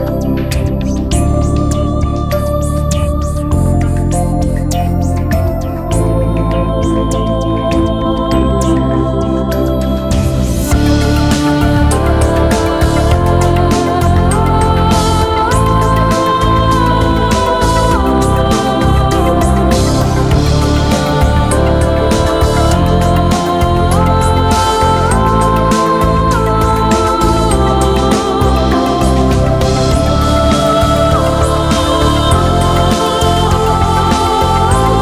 Звучание mp3 (lossy)